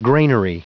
Prononciation du mot granary en anglais (fichier audio)
Prononciation du mot : granary